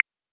GenericButton2.wav